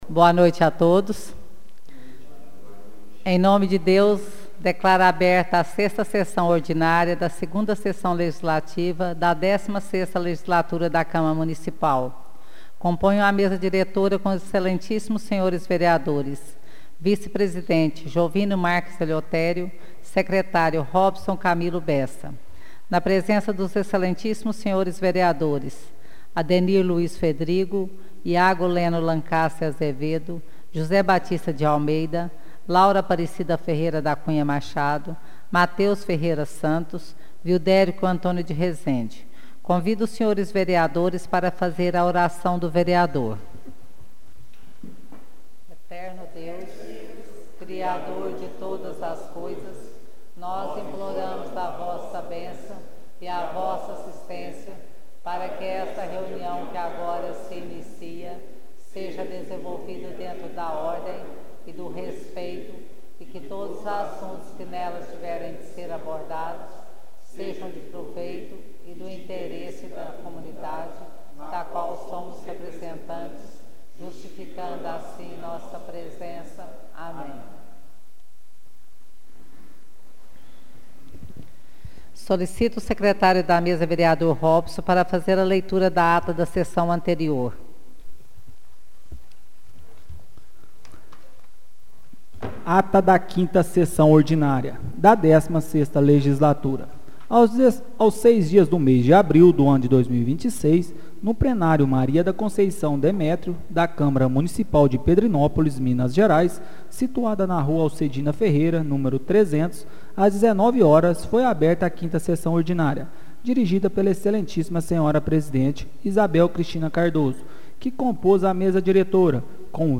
Áudio da 6ª Sessão Ordinária de 2026